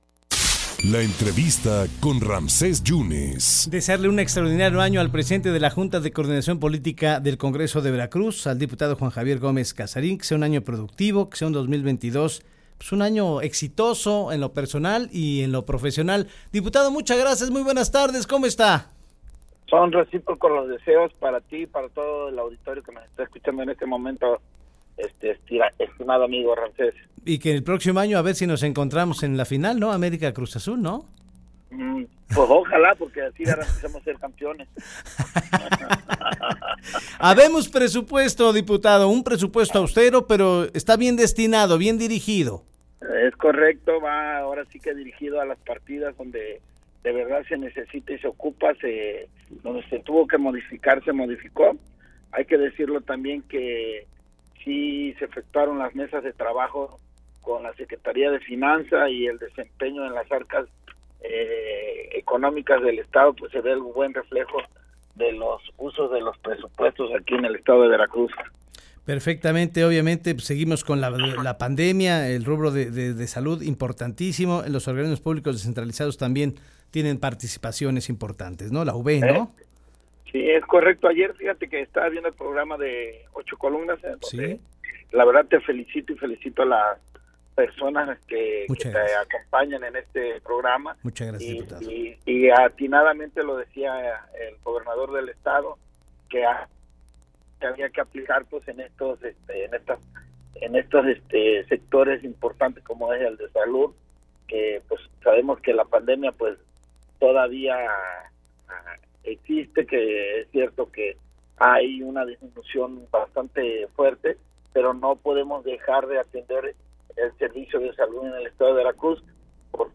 Redacción/Xalapa.- El diputado local Juan Javier Gómez Cazarín, platicó para En Contacto sobre el Presupuesto 2022 y algunas de las actividades prioridad en la cámara para el próximo año.